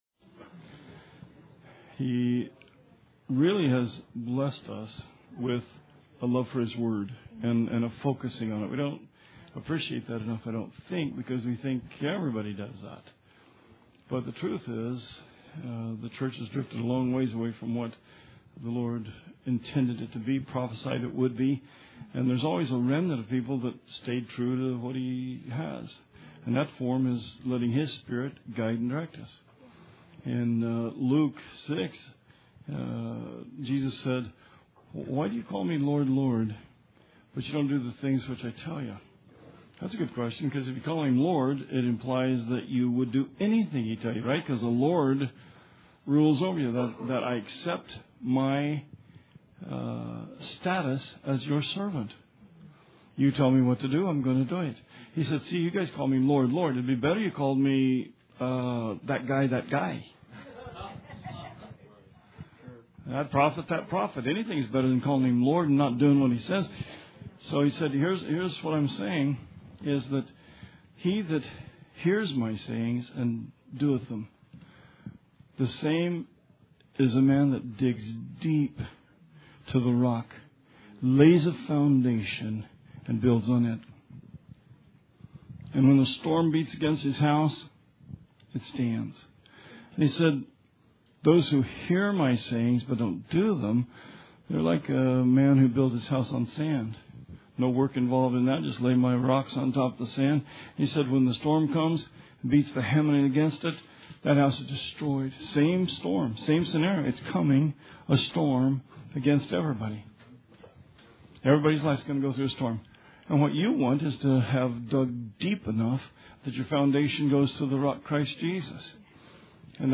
Sermon 8/21/16